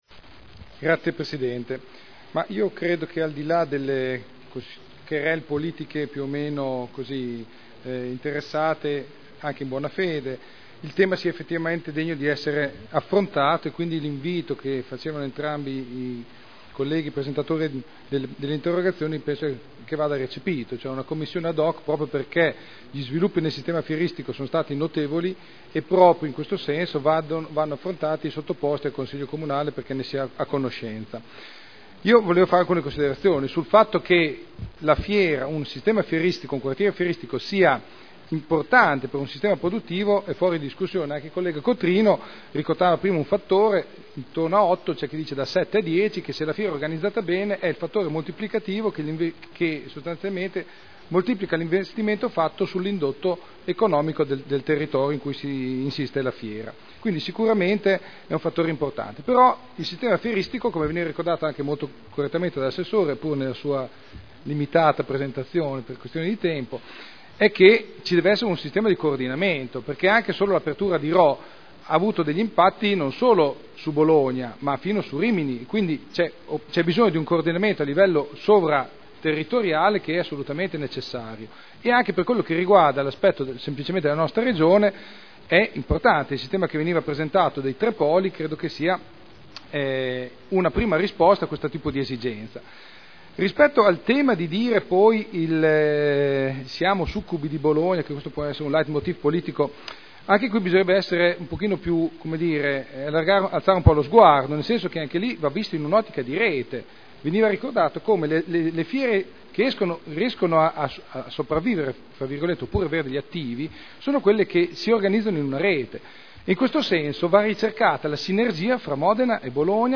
Seduta del 24/01/2011.